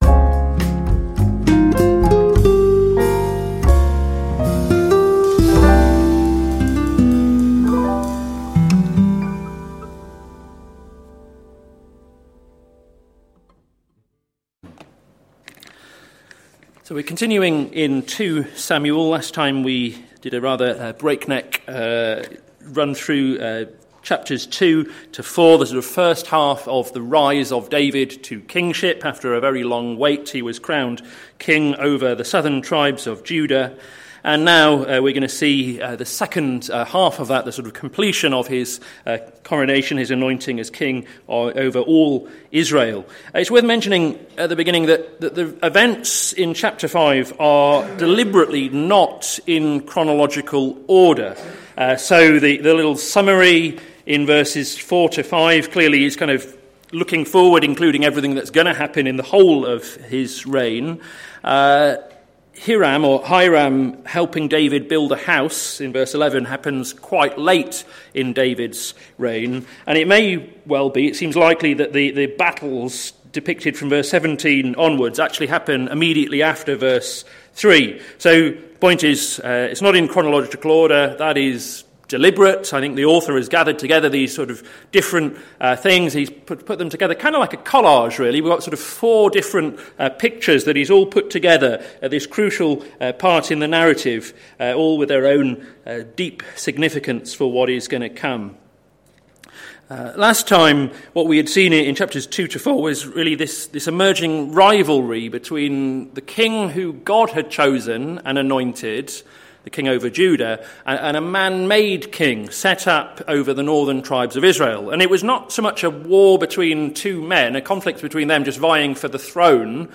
Sermon Series - May the Lord establish His Word - plfc (Pound Lane Free Church, Isleham, Cambridgeshire)